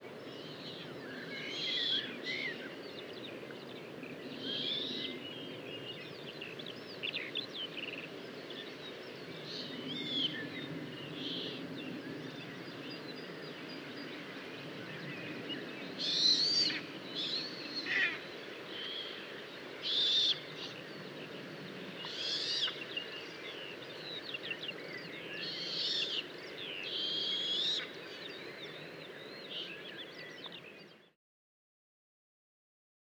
Eurasian Eagle-owl
40-Eurasian-Eagle-Owl-Distraction-Display-Of-Presumed-Female.wav